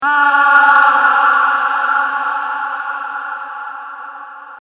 Death Yell2